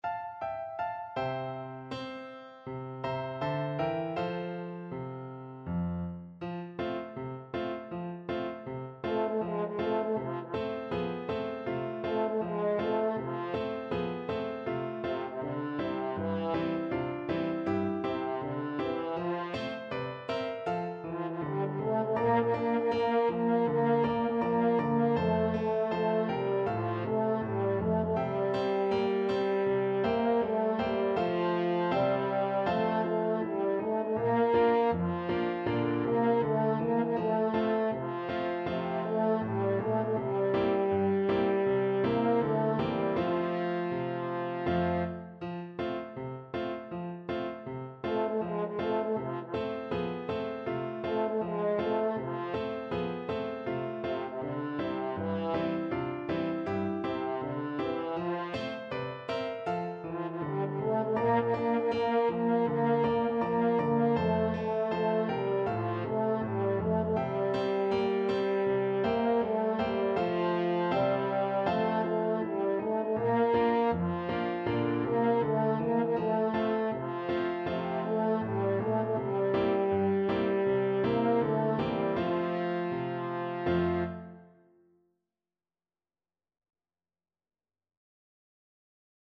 4/4 (View more 4/4 Music)
C4-Bb4
Humorously, two in a bar swing =c.80